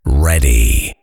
TowerDefense/Assets/Audio/SFX/Voiceover/ready.ogg at main
ready.ogg